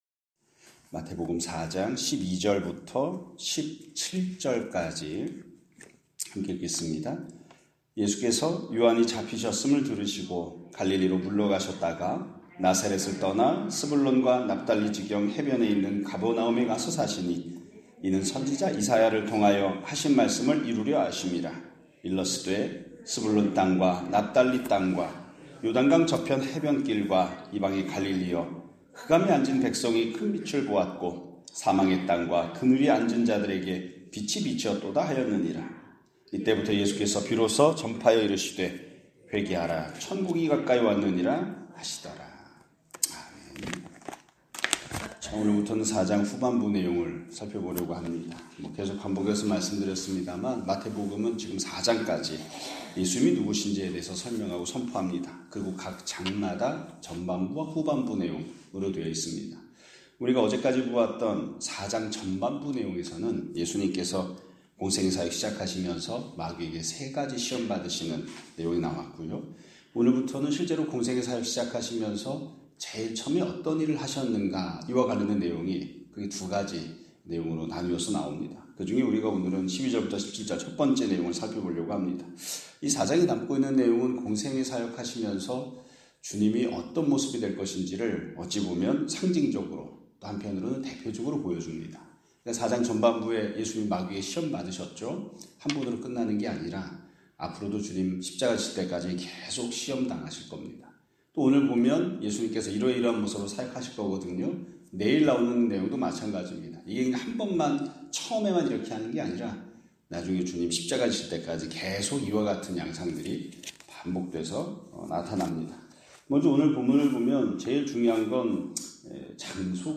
2025년 4월 30일(수요일) <아침예배> 설교입니다.